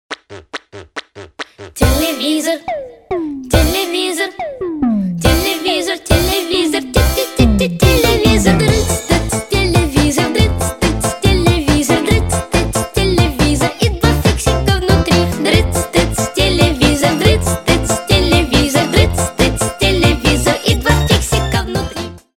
• Качество: 320, Stereo
позитивные
громкие
забавные
детский голос
Веселая детская песенка